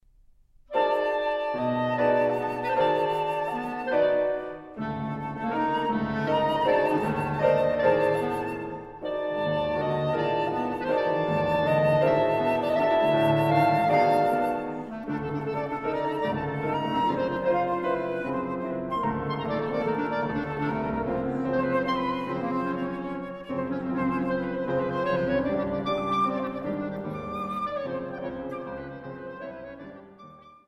Flute
Clarinet
Piano.